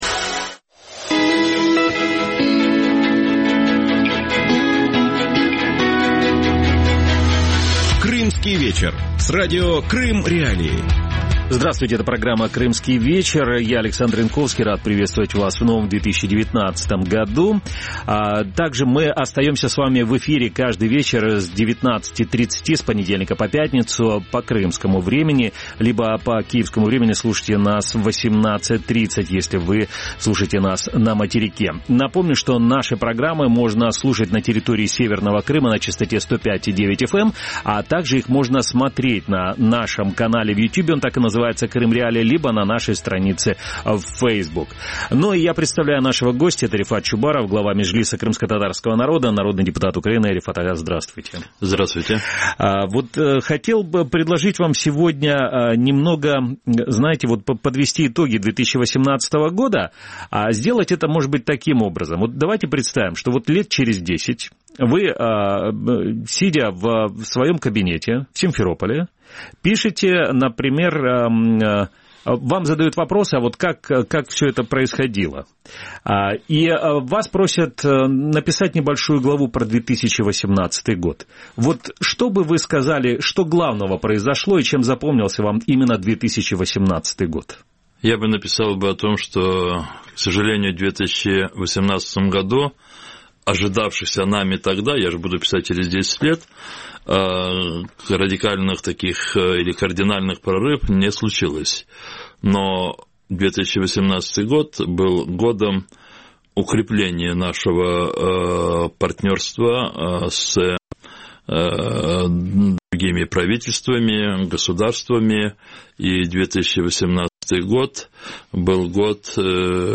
Что в 2018 году сделала Украина для деоккупации Крыма? Гость студии: Рефат Чубаров, глава Меджлиса крымскотатарского народа, народный депутат Украины.